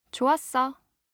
알림음 8_좋았어2-여자.mp3